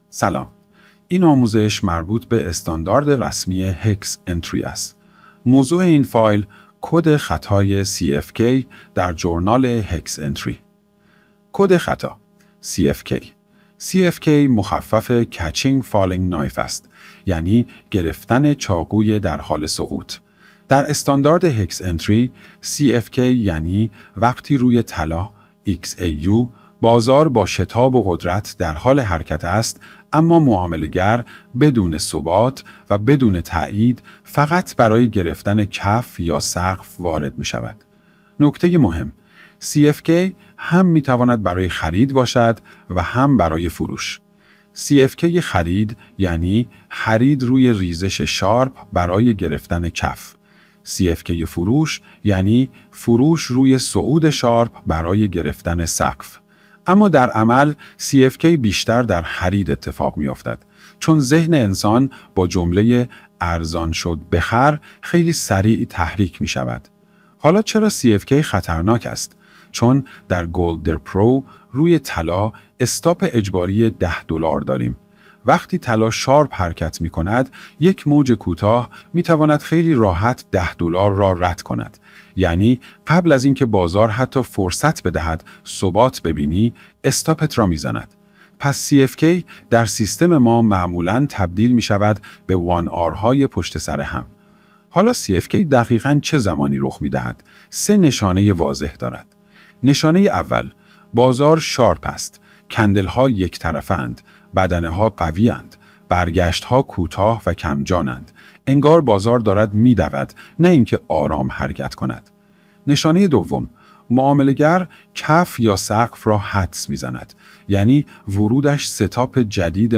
نسخهٔ صوتی آموزش